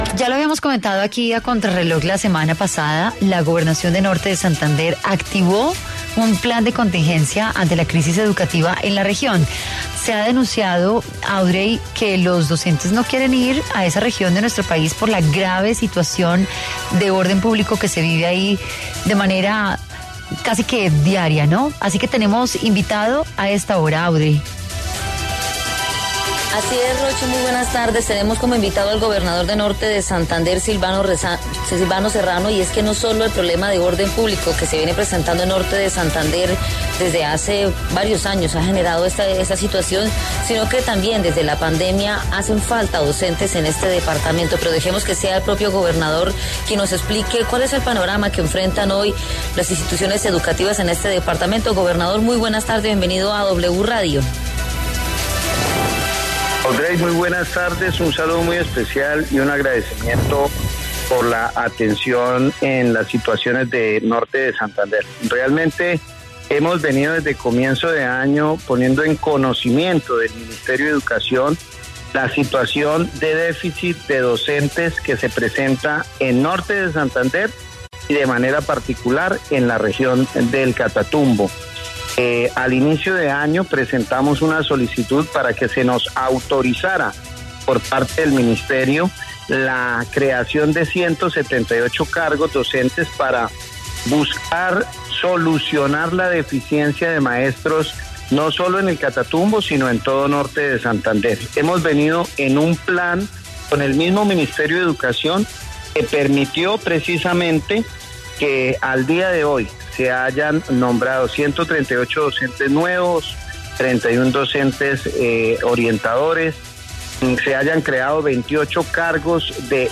El gobernador de Norte de Santander Silvano Serrano en diálogo con Contrarreloj de W Radio aseguró “hemos venido desde comienzo de año, poniendo en conocimiento del Ministerio de Educación la situación de déficit de docentes que se presenta en Norte de Santander y de manera particular en la región del Catatumbo”.